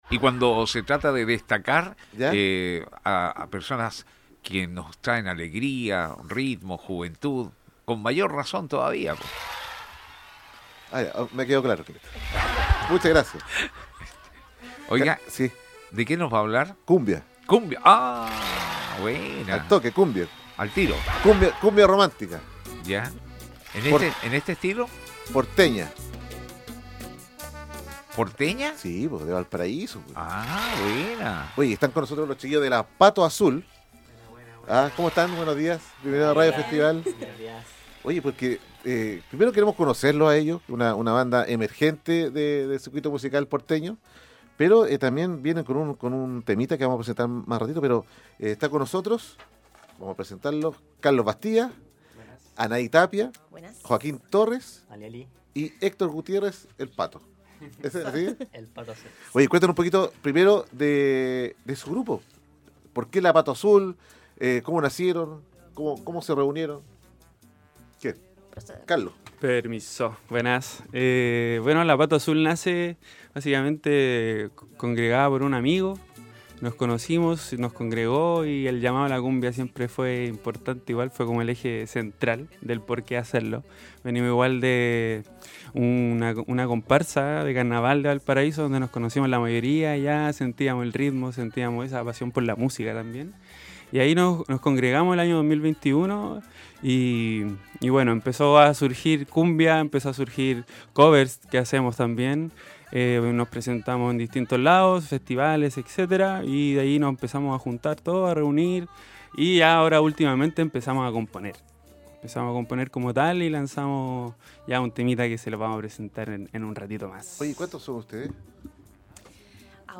La agrupación musical, oriunda de Valparaíso
cumbia romántica